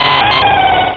pokeemerald / sound / direct_sound_samples / cries / lairon.aif